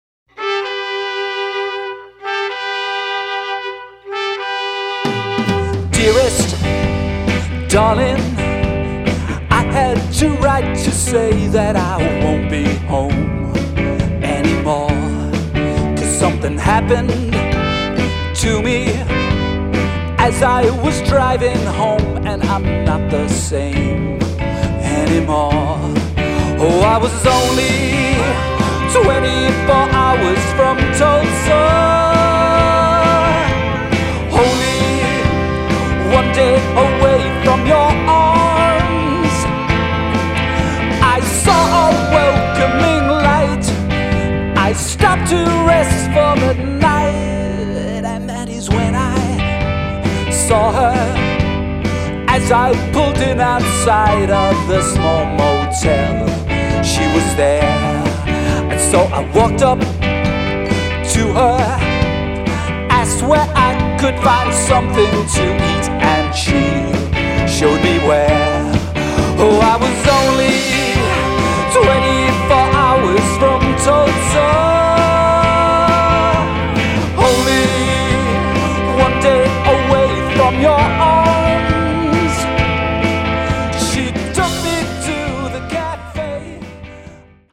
flute
guitar
drums
soulful trumpet and vocals
Live Audio Samples